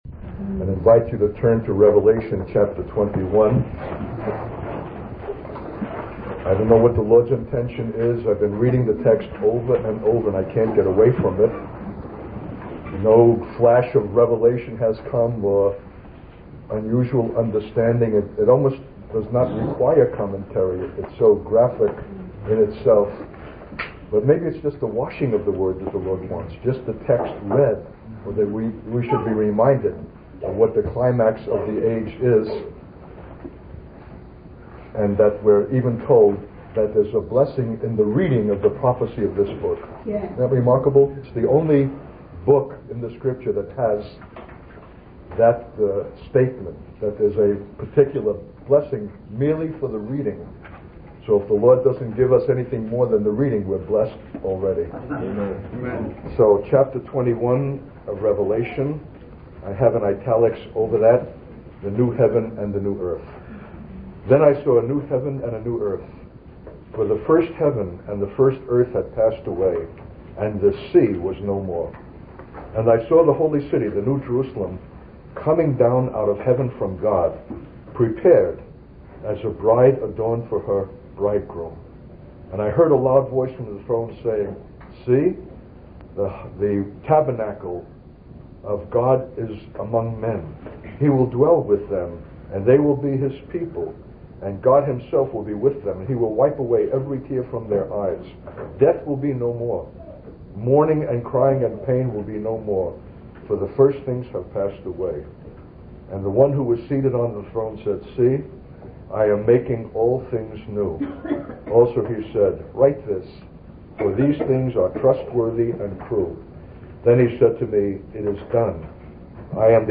In this sermon, the preacher emphasizes the misuse and abuse of the word 'government' in our generation, associating it with self-aggrandizing politicians and corrupt practices.